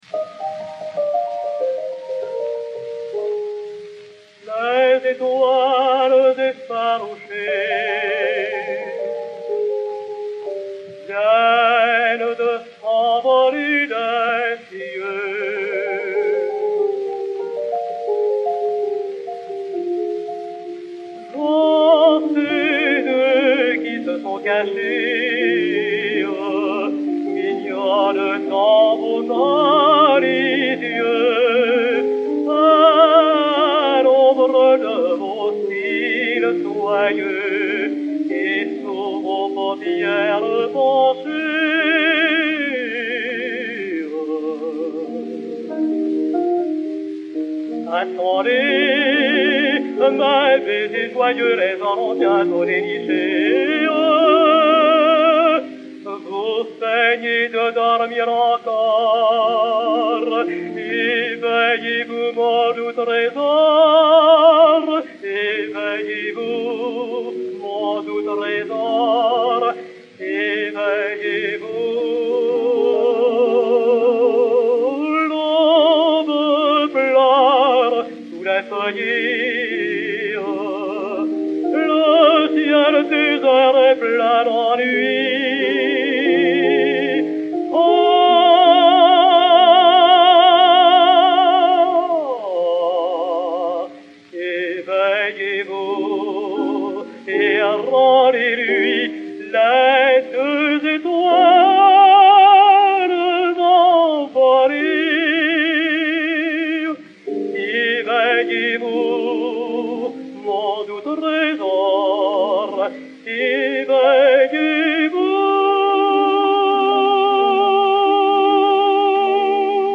Edmond Clément, ténor, et Frank La Forge, piano
B-11189-1, enr. à Camden, New Jersey, le 06 novembre 1911